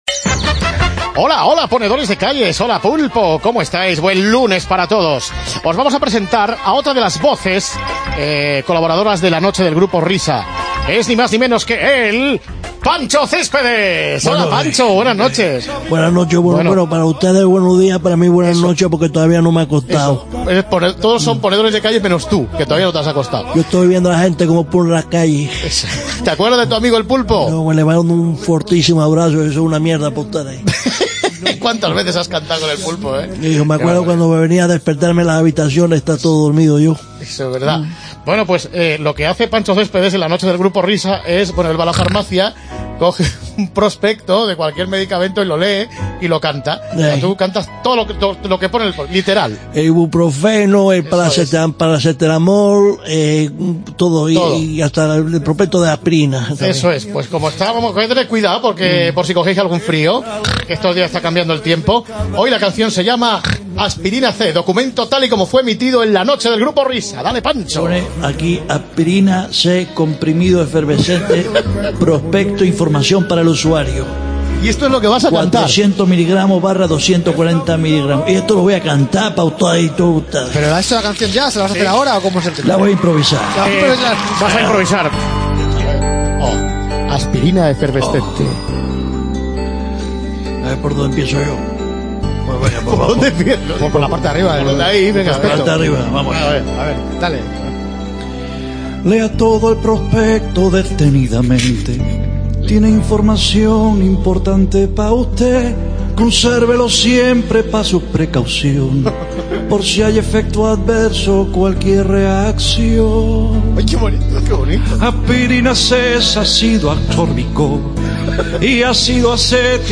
El toque de humor